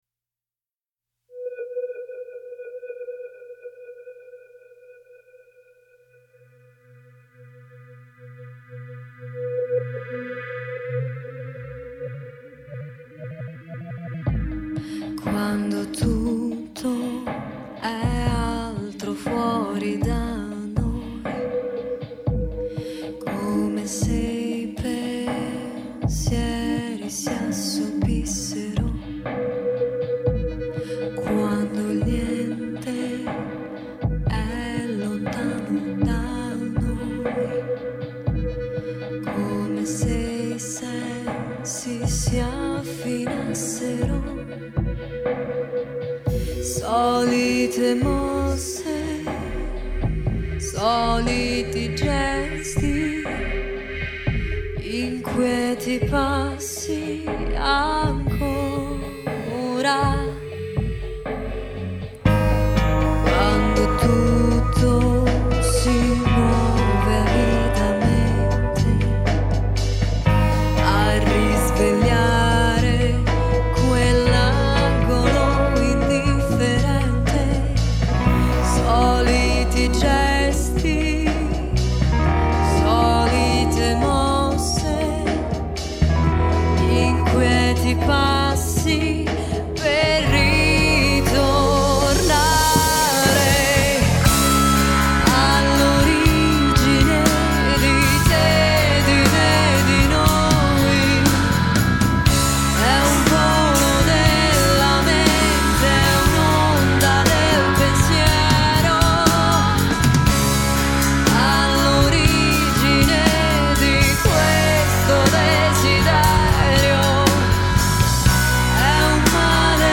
Progressive Rock